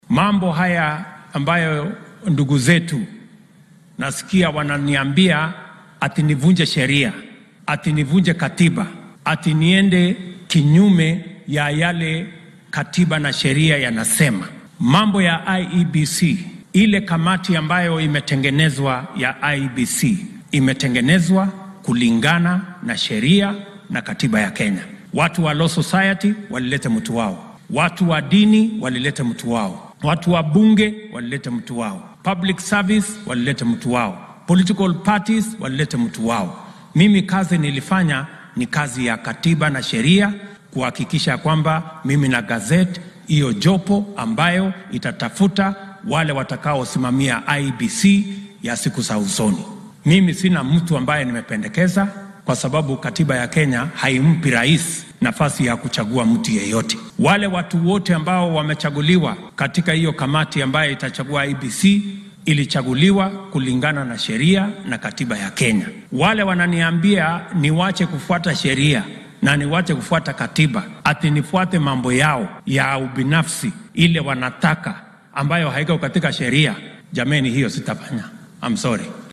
Hasa ahaate madaxweyne Ruto ayaa xilli uu ku sugnaa ismaamulka Tharaka Nithi sheegay in keliya uu ansixiyay xubnaha guddiga soo xulaya howlwadeennada IEBC ka dib markii loo soo gudbiyay liiska iyadoo la tixgeliyay matalaadda dhinacyada kala duwan.